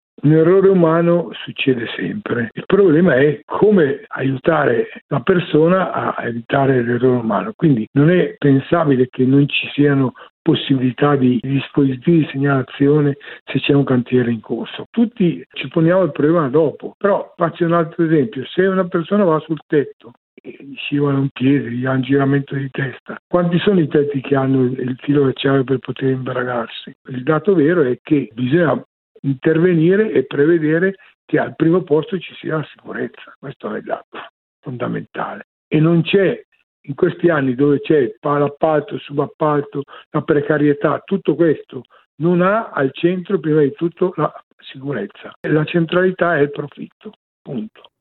Dice ai nostri microfoni Tino Magni, senatore di Alleanza Verdi e Sinistra.